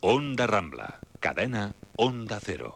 Indicatius de l'emissora